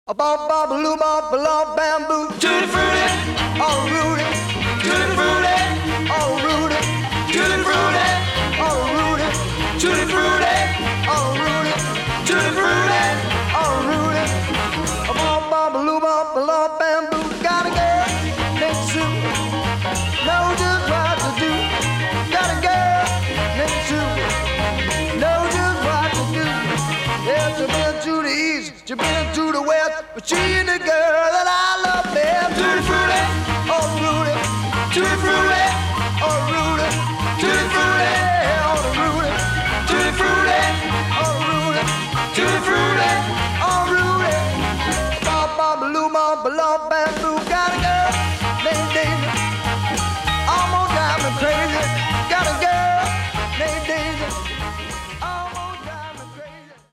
Recorded at: PYE International Studios, London